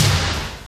TOM BIG T08R.wav